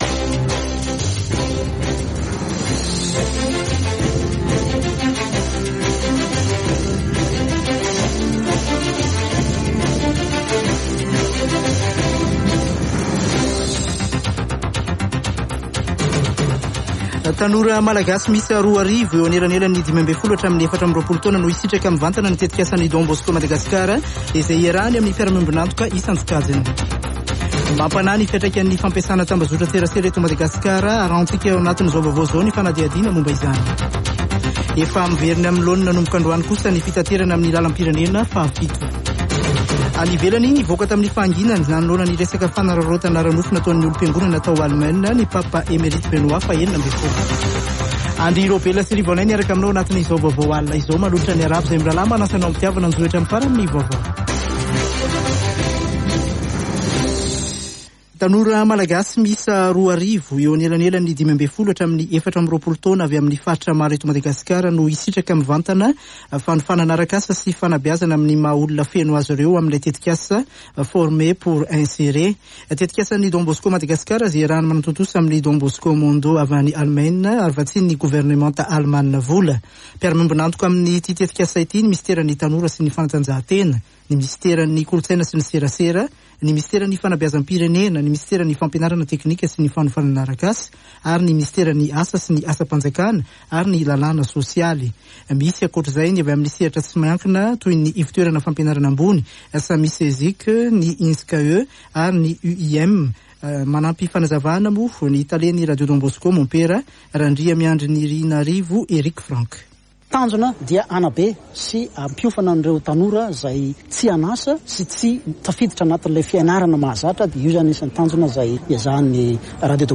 [Vaovao hariva] Alarobia 9 febroary 2022